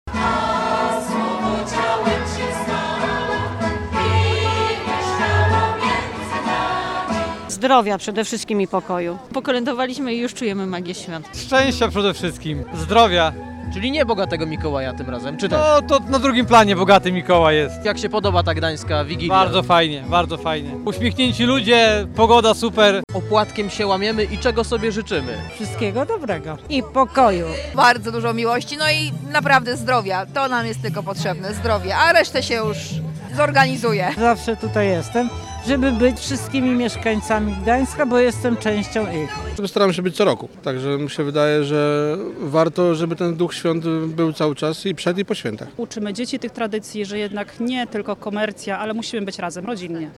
Było świąteczne kolędowanie, życzenia oraz dzielenie się opłatkiem i pieczywem. Kilkaset osób pojawiło się w to popołudnie na Długim Targu w Gdańsku, gdzie odbyła się coroczna Gdańska Wigilia.
Czego życzyli sobie gdańszczanie i turyści?